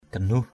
/ɡ͡ɣa˨˩-nuh˨˩/